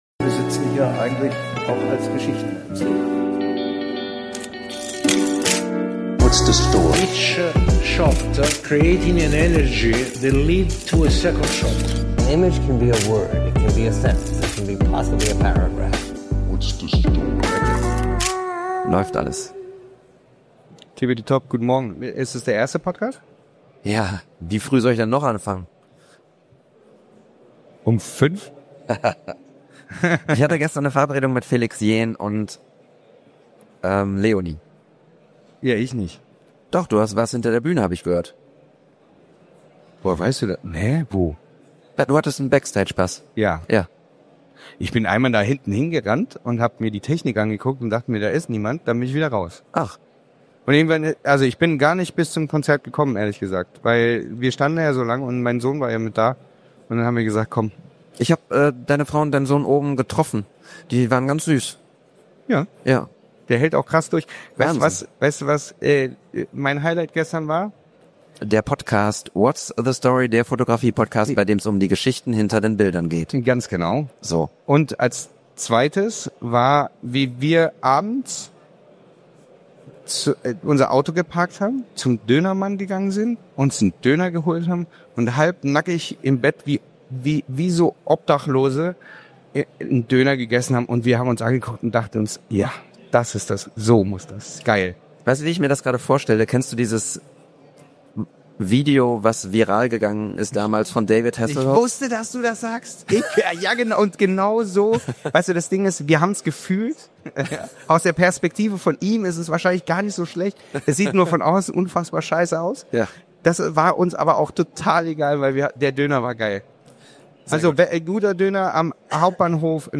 Live von der Imaging World: Wale, Koalas, Sonne – und ein Fotograf, der das Glück sucht (und findet).